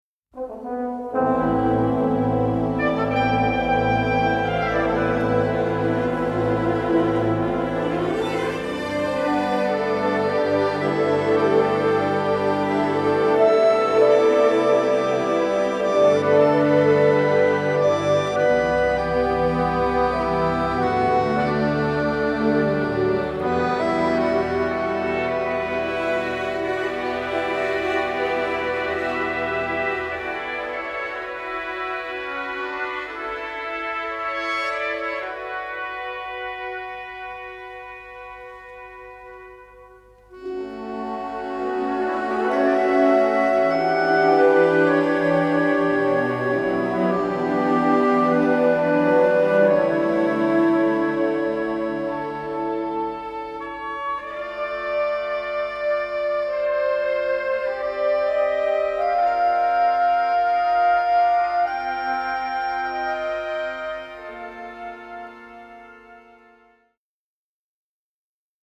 striking western score
unedited 2-track stereo masters